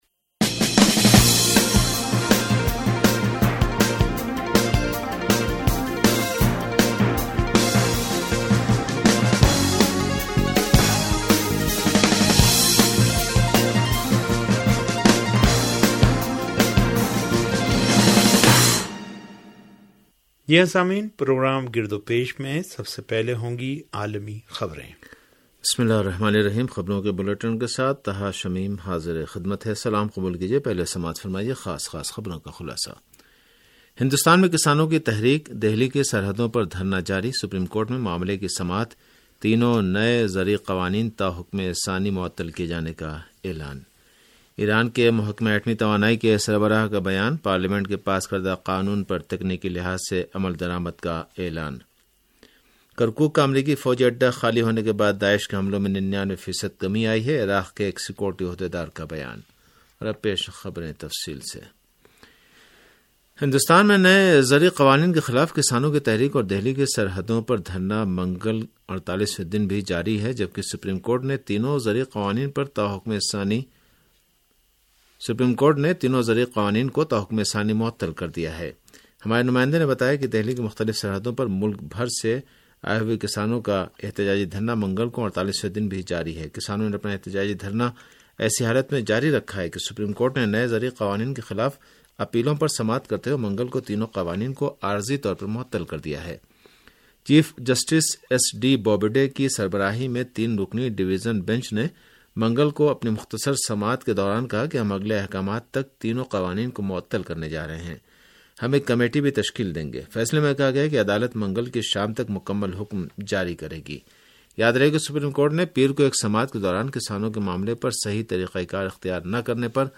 ریڈیو تہران کا سیاسی پروگرام گرد و پیش